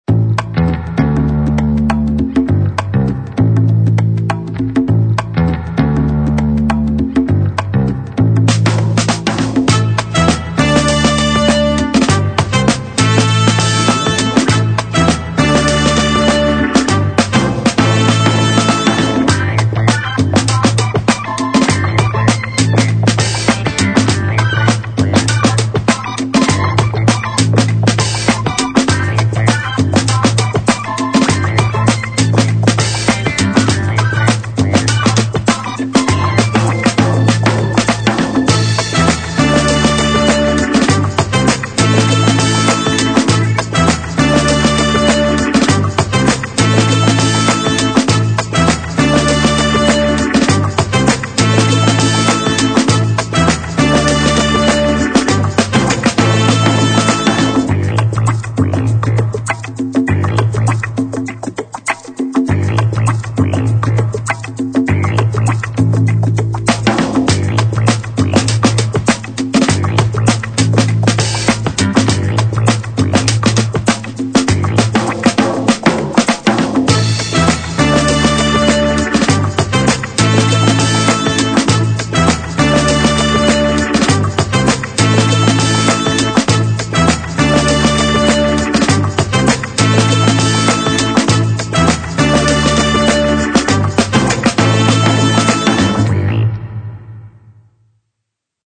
描述：强大而绝妙的放克音轨与电影，抢劫电影的感觉就像在海洋的十一，抢夺和更多。